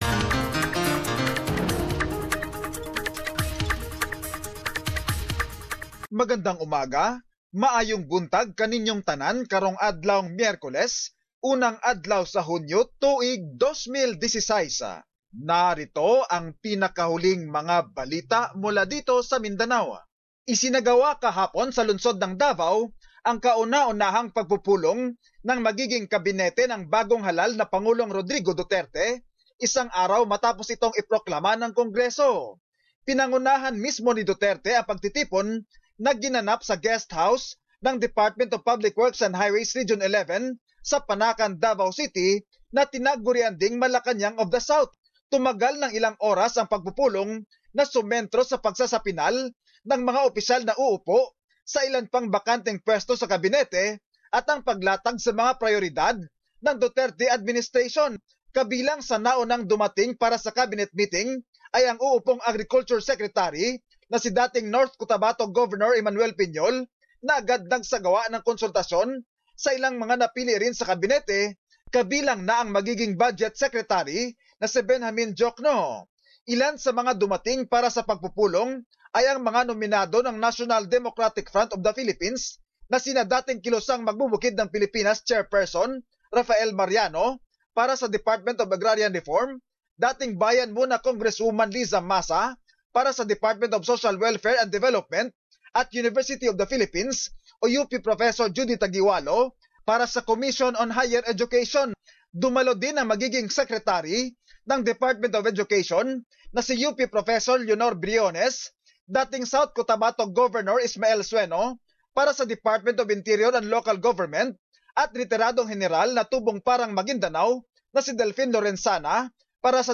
Mindanao News.